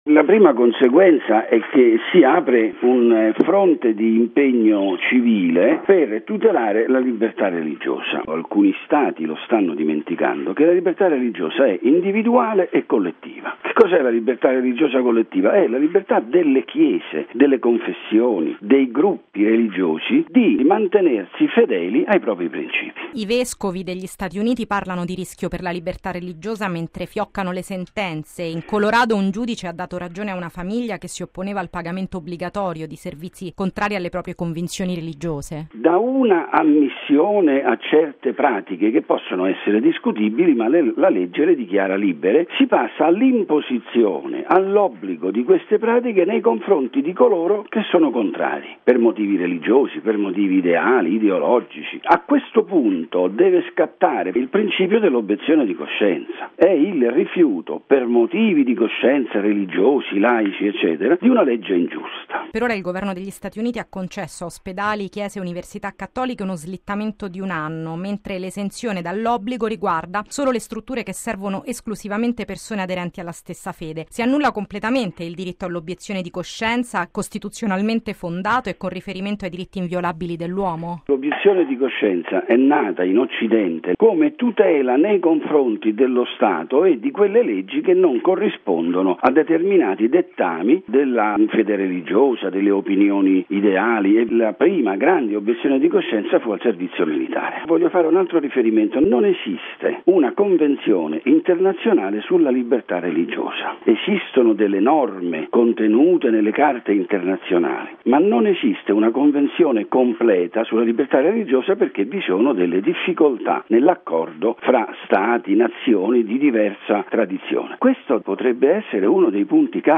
Un’imposizione che per le organizzazioni religiose slitterà di un anno, e che di fatto - dicono i vescovi - è una violazione della libertà religiosa e del diritto all’obiezione di coscienza. Quali le conseguenze?